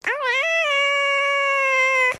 звуки животных
мяу